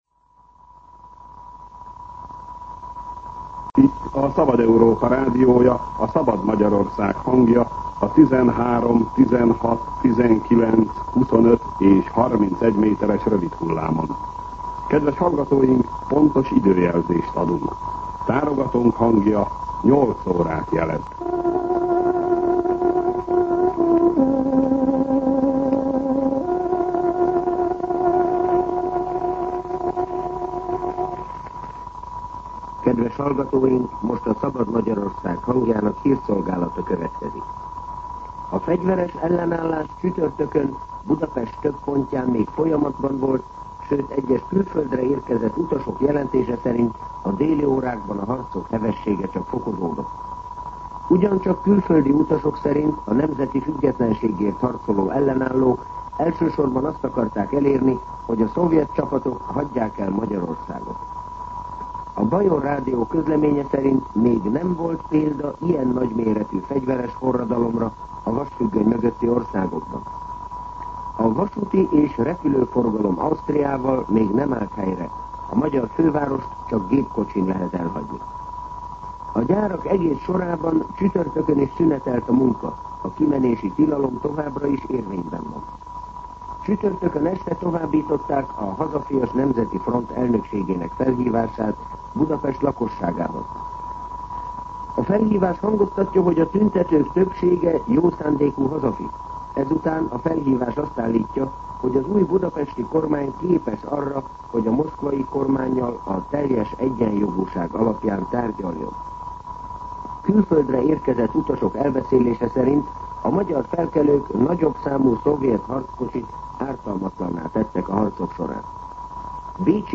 08:00 óra. Hírszolgálat
Tárogatónk hangja 8 órát jelez.